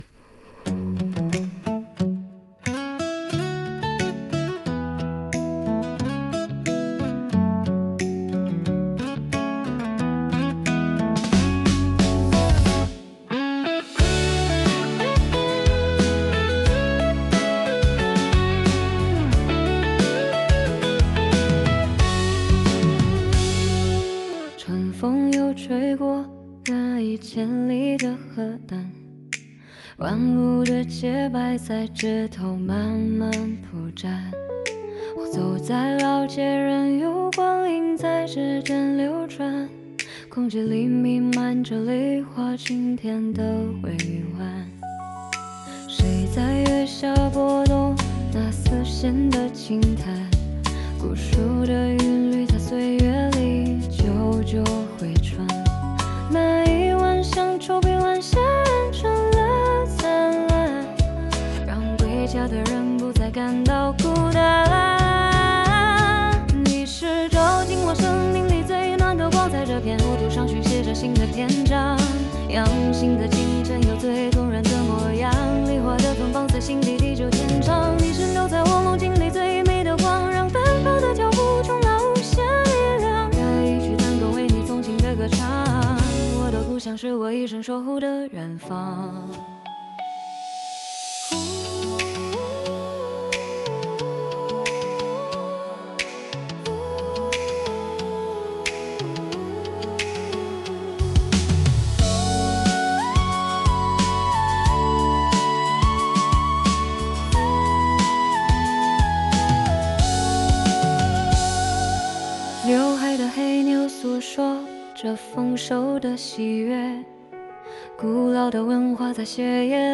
梨乡谣 (女声)